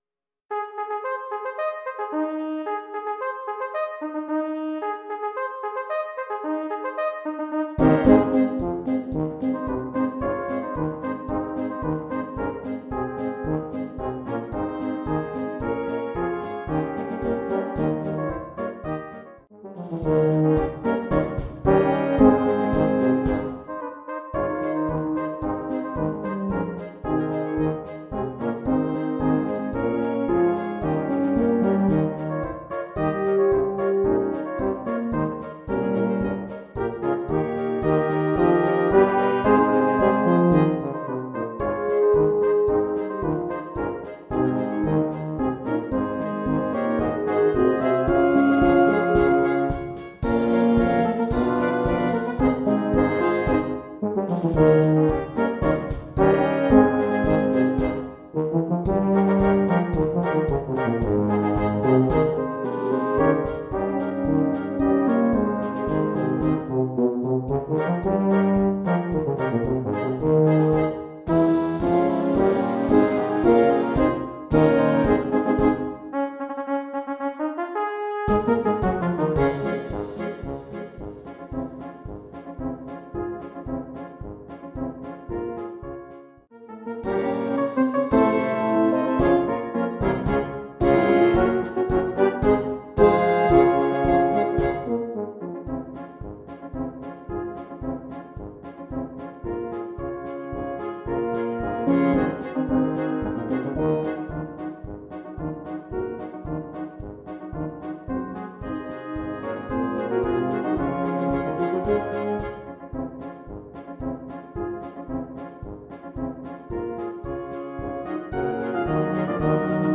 Mittelschwer, Dauer ca. 2:40 min
Ein schwungvoller Marsch im Stile alter Regimentsmärsche.